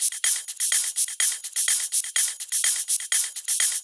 Live Percussion A 09.wav